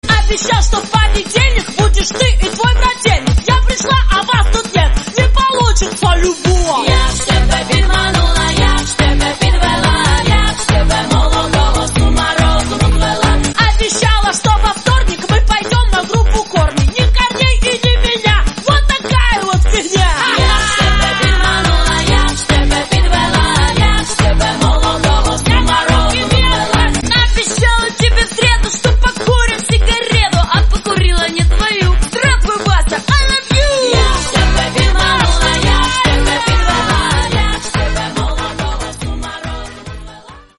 • Качество: 64, Stereo
забавные
веселые
женский голос